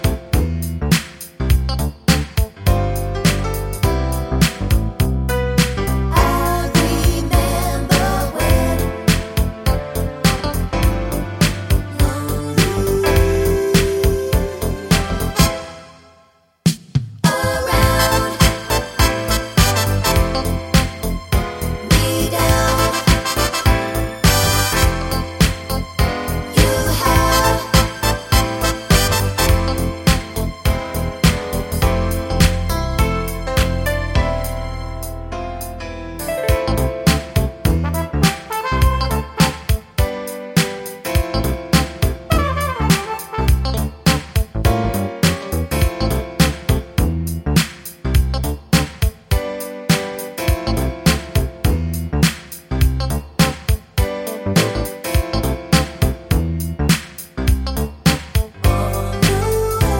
no Backing Vocals Soul / Motown 3:40 Buy £1.50